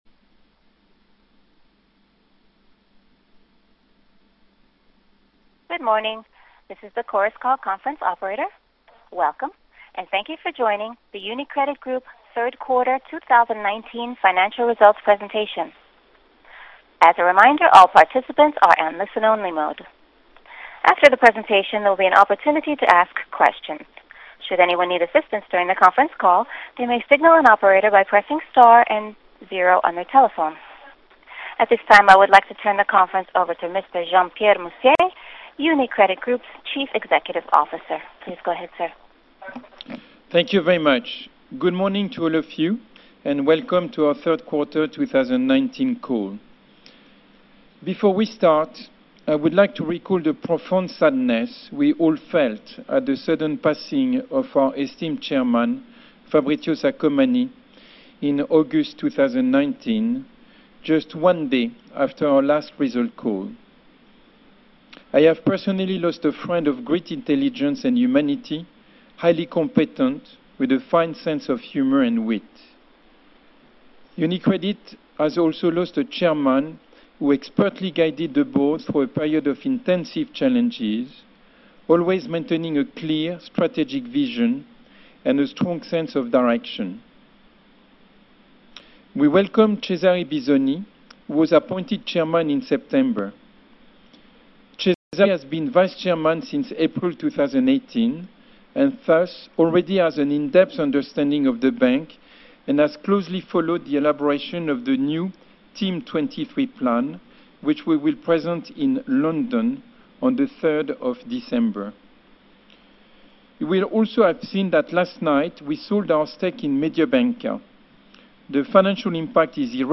Presentazione dei risultati di Gruppo 3Q19 e 9M19 (pubblicazione risultati e conference call)